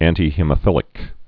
(ăntē-hēmə-fĭlĭk, ăntī-)